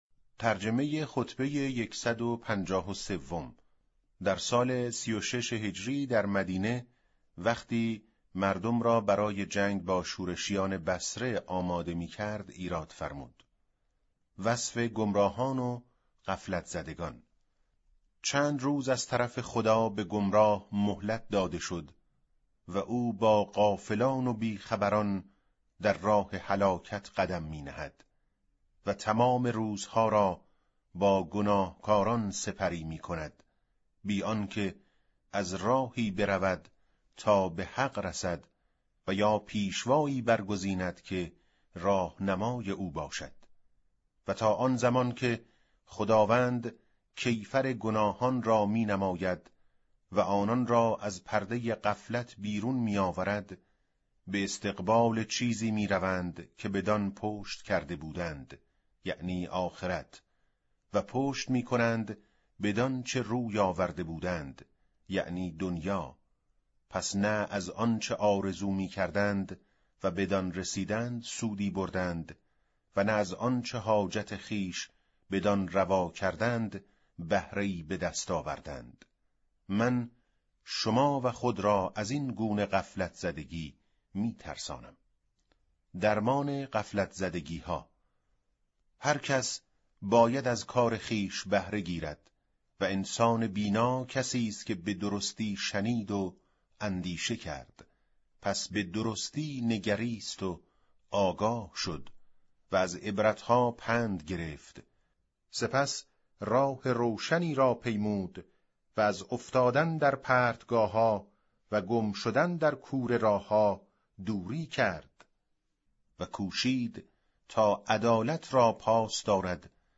به گزارش وب گردی خبرگزاری صداوسیما؛ در این مطلب وب گردی قصد داریم، خطبه شماره ۱۵۳ از کتاب ارزشمند نهج البلاغه با ترجمه محمد دشتی را مرور نماییم، ضمنا صوت خوانش خطبه و ترجمه آن ضمیمه شده است: